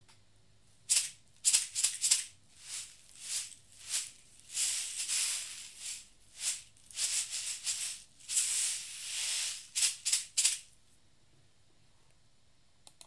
Basket Rattle from Kenya. Cane and wood, filled with small pebbles or seeds. Gentle sound.
Framed-reed-shaker.mp3